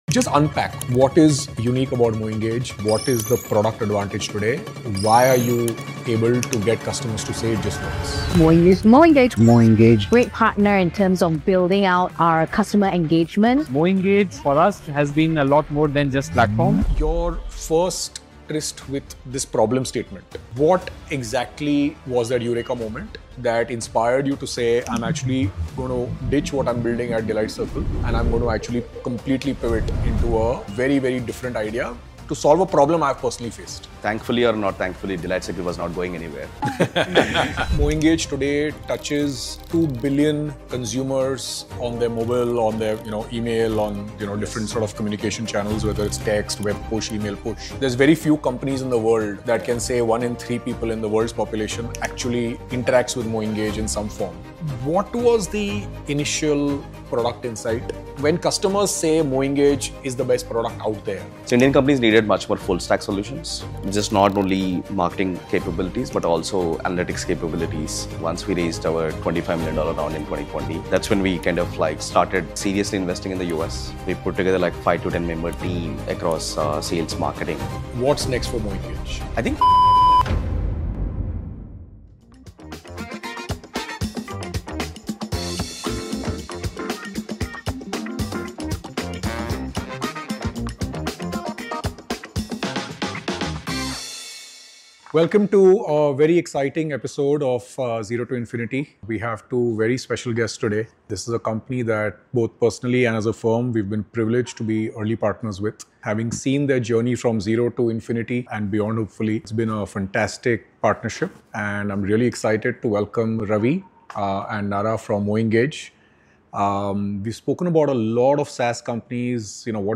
In a wide-ranging chat, they discuss the MoEngage journey - from initial failures to serving over 2Bn users.